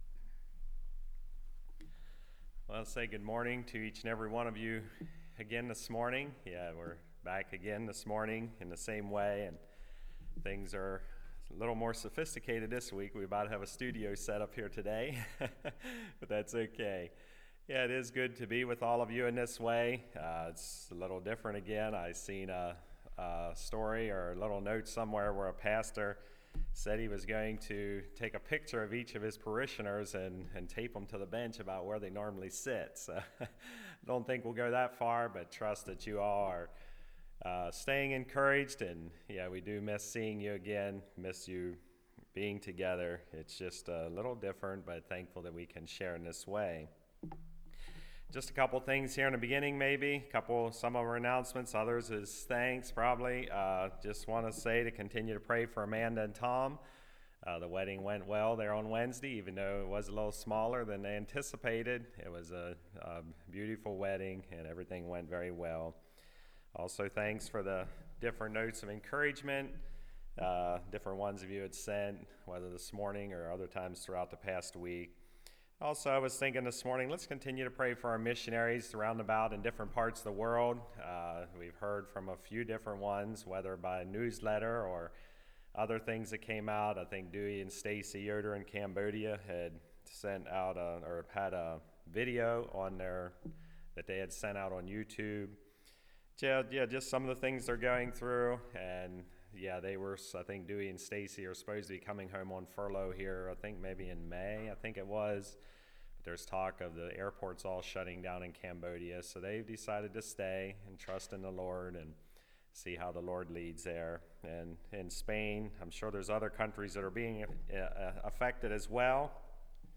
Passage: Psalm 46:1-11 Service Type: Message